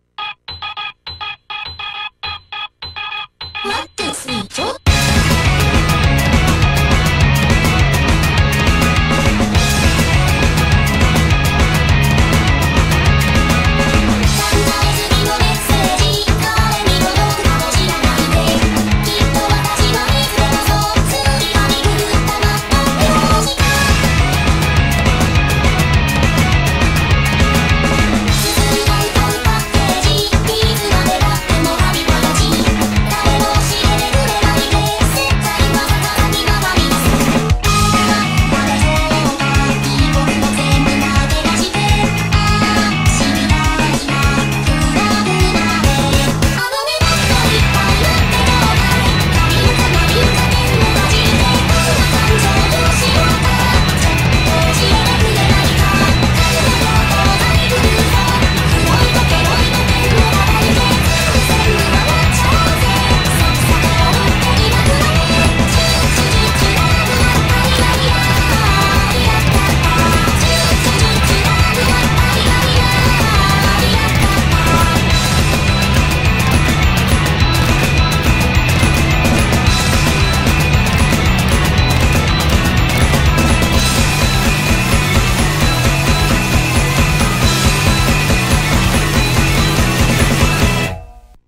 BPM205-296
Audio QualityPerfect (Low Quality)